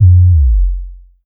808 - Slide.wav